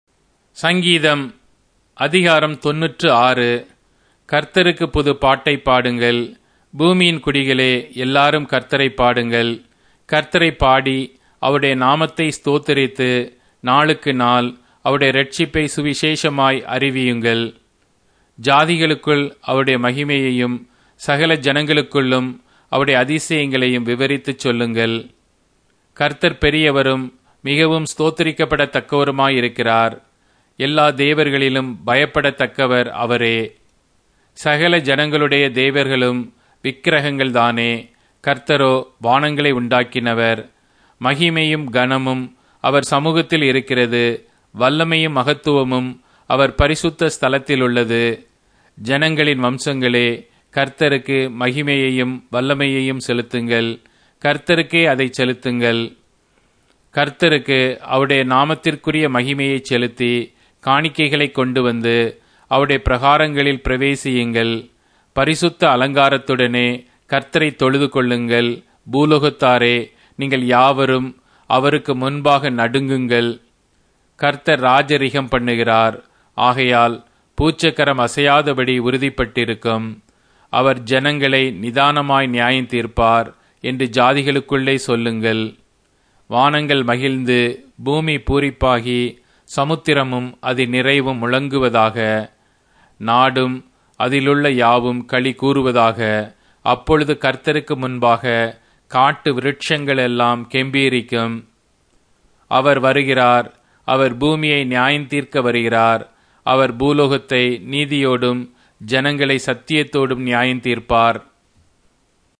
Tamil Audio Bible - Psalms 91 in Mrv bible version